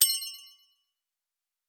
Special & Powerup (31).wav